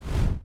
Added some sound effects
whoosh.mp3